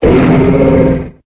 Cri de Polagriffe dans Pokémon Épée et Bouclier.